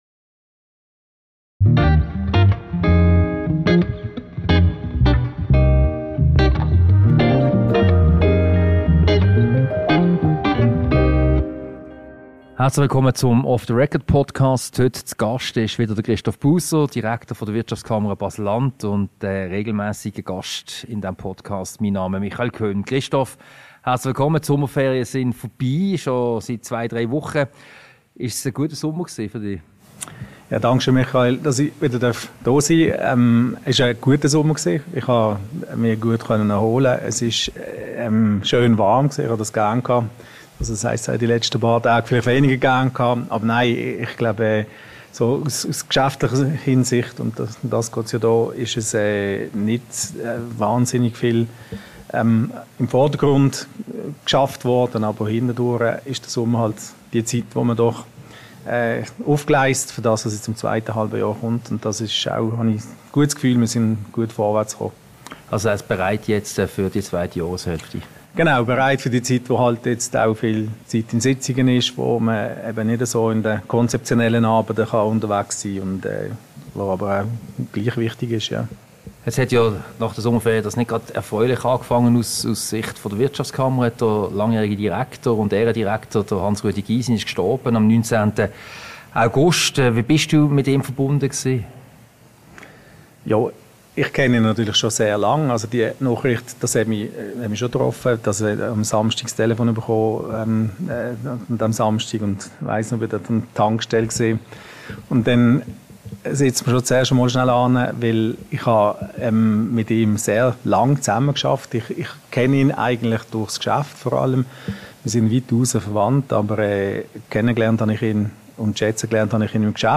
Ein Gespräch über Hans Rudolf Gysin, die Rheinstrasse und andere aktuelle Themen.